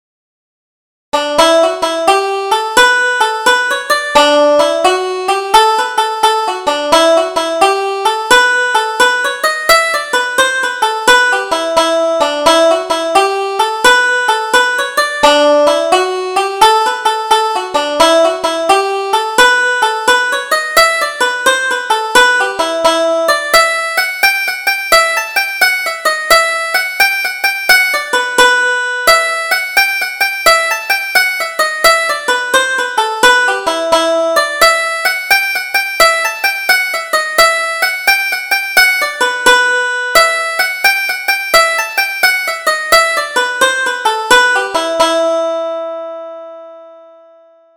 Double Jig: Lannigan's Ball